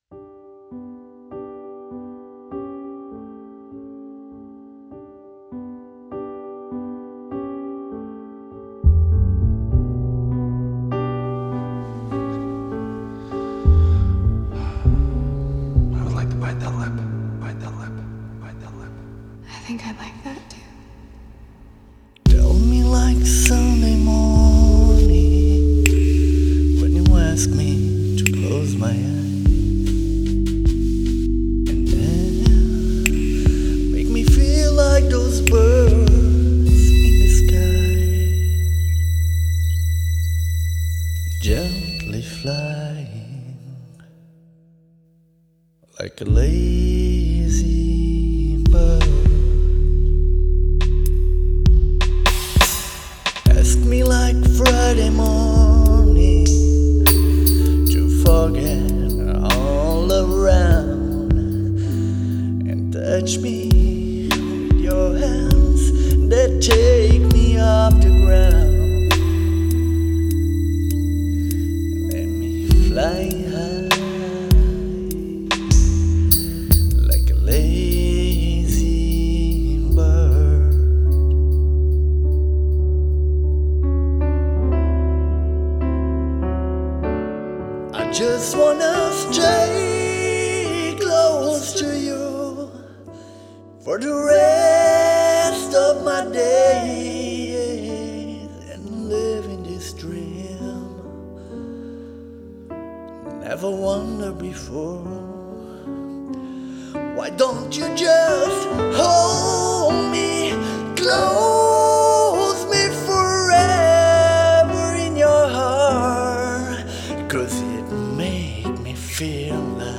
vocals/instrumental